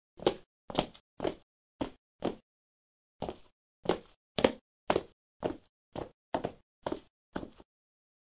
脚步雪
描述：在雪中的脚步声
标签： 场记录 脚步声 现在
声道立体声